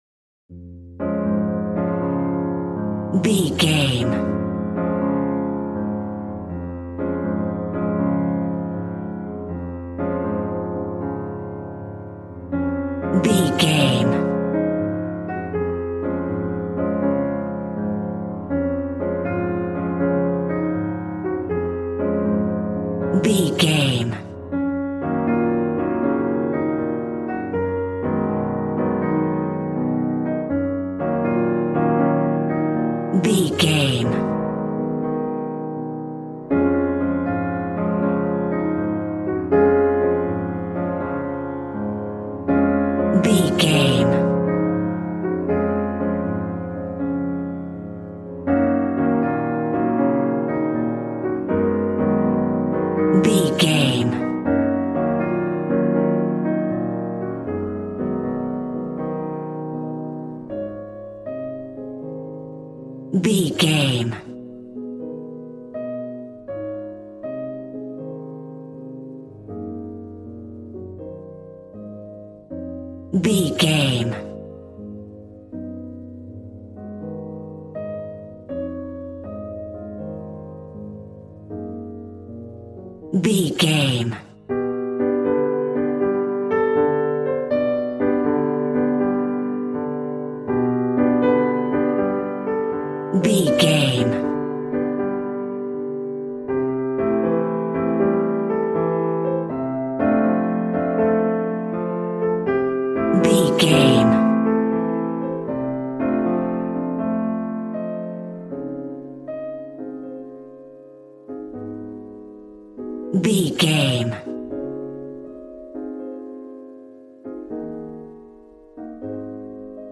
Ionian/Major
D♭
piano
drums